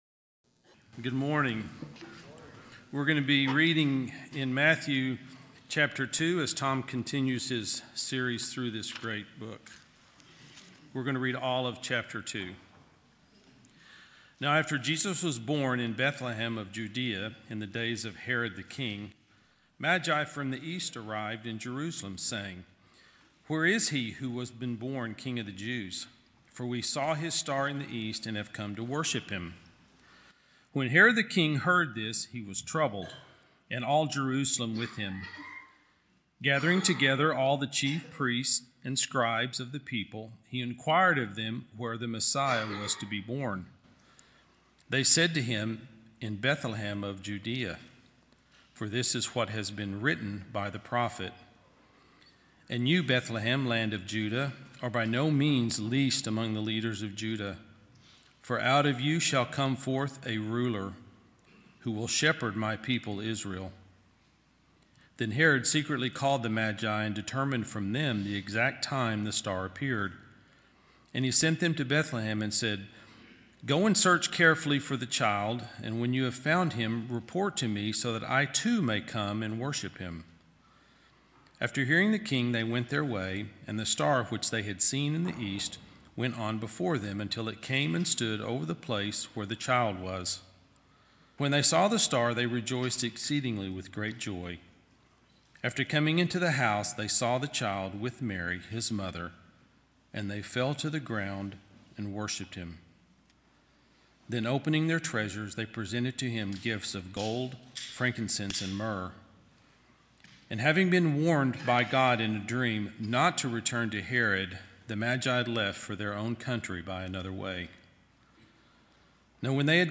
Sermons - Community Bible Chapel, Richardson, Texas podcast